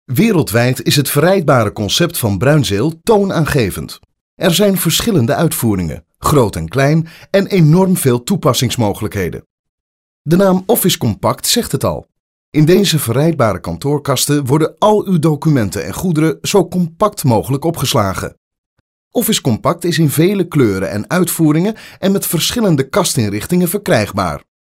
My voice is frequently asked for business, hardselling, friendly and comical tone of voices.
My categories: Mid to low range, powerful, Authoritative, Bass-Baritone, Big, Deep Dynamic, Versatile, Dramatic, Contemporary, Intense, Raw, Flat, Monotone, Subdued, Real, Natural, Hard-Sell, Professional, Corporate, Conversational, Guy-Next-Door Bright, Warm, Clean, Crisp, Clear, Energetic, Fresh, Comedy, Cute, Goofy, Character, Wacky, Cartoon, Maternal, Sarcastic, Genuine, Friendly, Fun, Confident, Enthusiastic, Easy Going, Pleasant, Friendly, Sincere, Knowledgeable, Compassionate, Believable, Intimate, Playful, Informative
Sprecher niederländisch, holländisch für Werbung, Imagefilme, Zeichentrick, DVD-Spiele etc.
Sprechprobe: Sonstiges (Muttersprache):